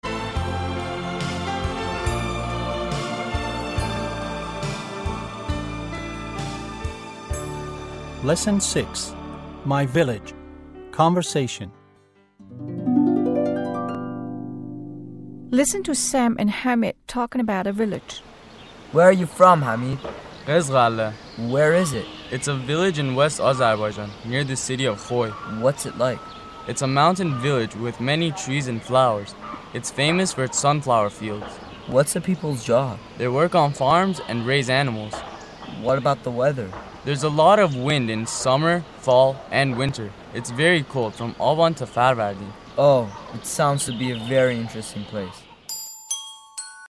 تمرین و فایل صوتی مکالمه درس 6 زبان هشتم
1-english-8-6-conversation.mp3